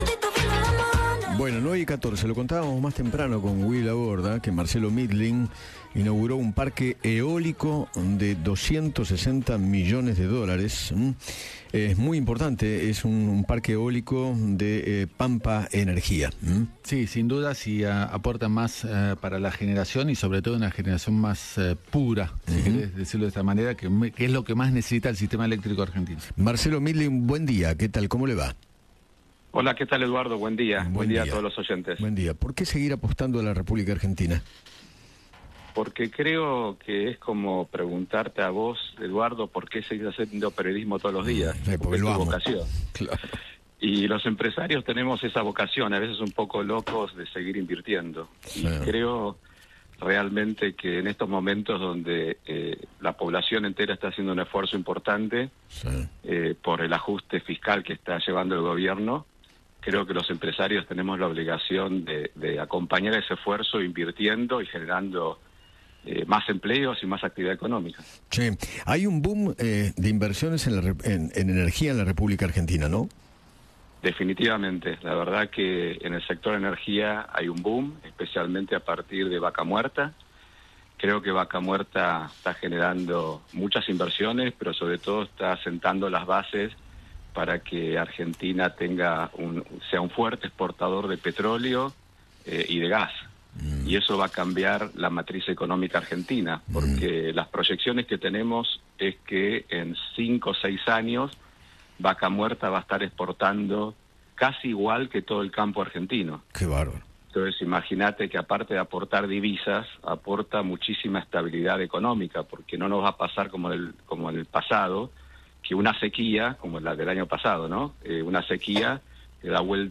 El Presidente de Pampa Energía, Marcelo Mindlin, habló con Eduardo Feinmann sobre la inauguración de un parque eólico de 260 millones de dólares, y reveló por qué apoya las medidas de Javier Milei.